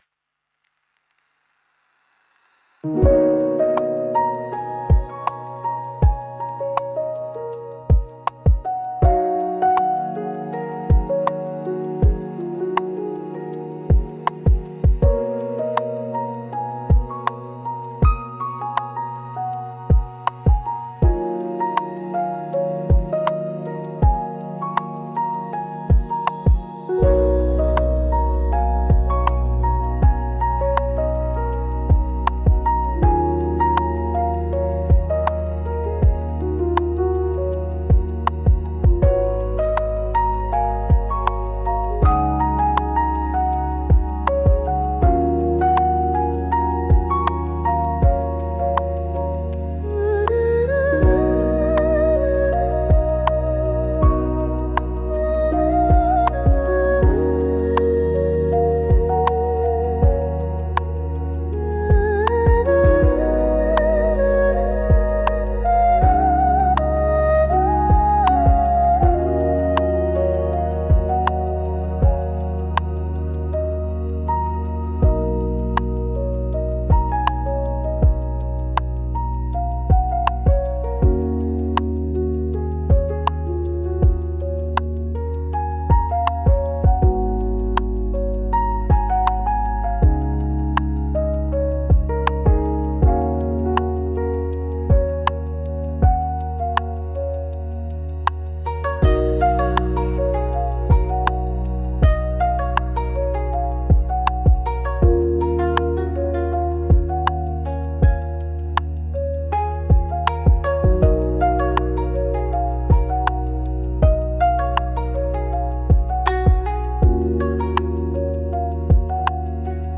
幻想的